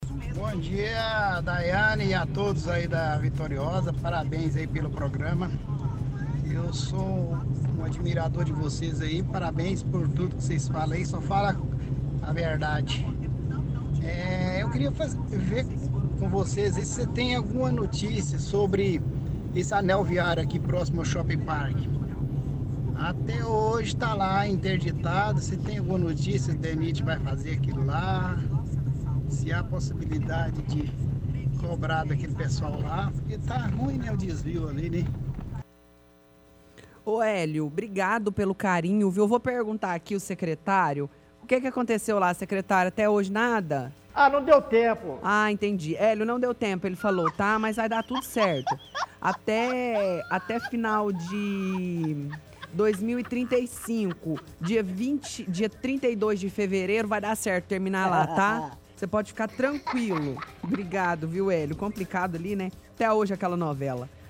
Transmissão de áudio do secretário Norberto, “ah, não deu tempo”.